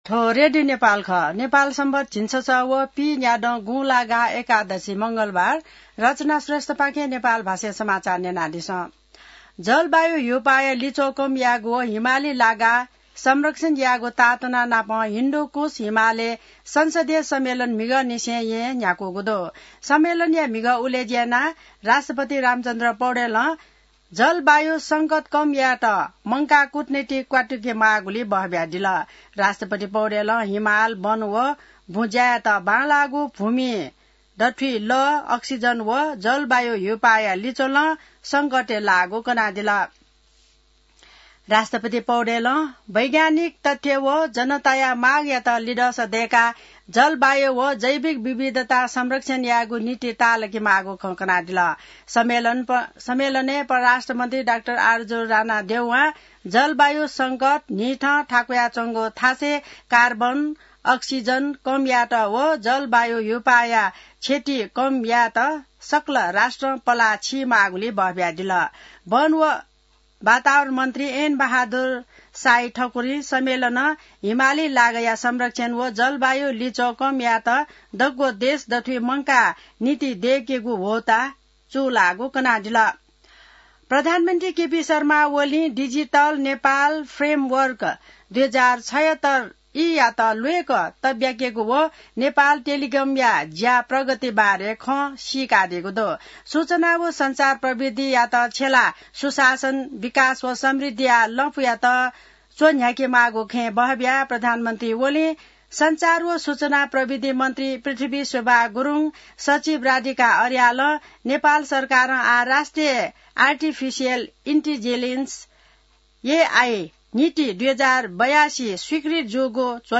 An online outlet of Nepal's national radio broadcaster
नेपाल भाषामा समाचार : ३ भदौ , २०८२